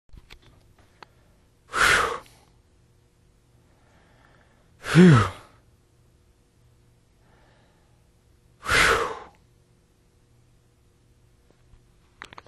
voice_breath